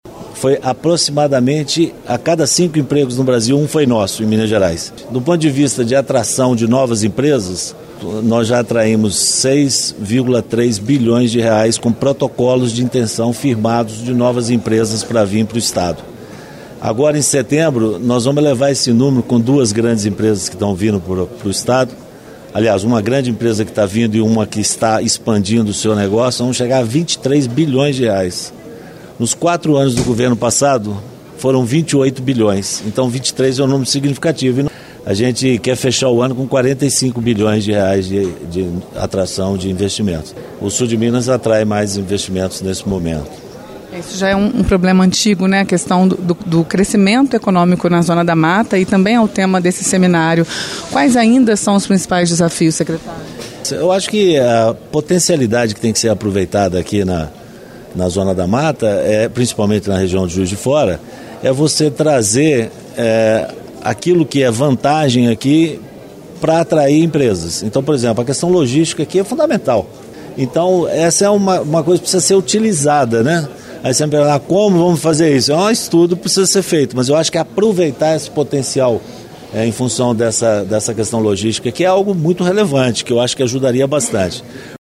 Em conversa à imprensa, o secretário – que pediu desligamento do cargo na última semana e aguarda a posse do sucessor – fez um balanço do trabalho frente à pasta. Segundo ele, foram gerados 100 mil empregos em Minas em 2019.
Manoel Vitor de Mendonça Filho,